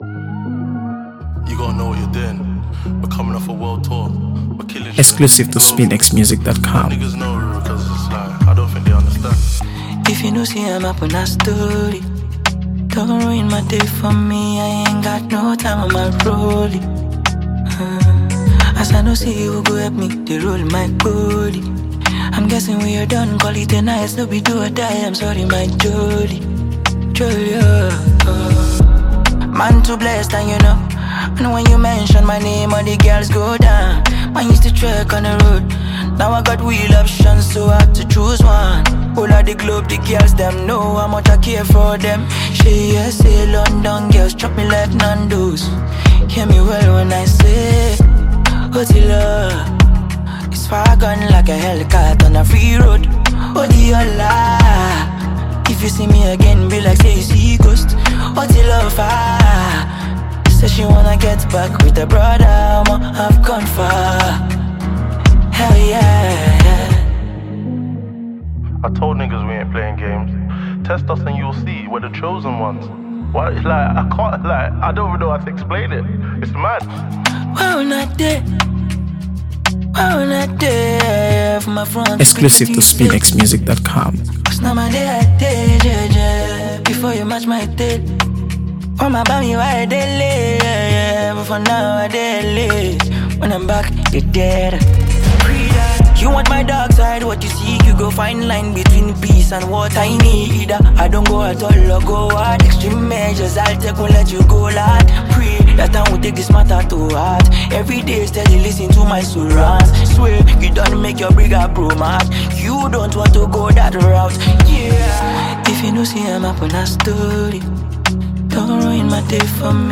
AfroBeats | AfroBeats songs
high-octane addition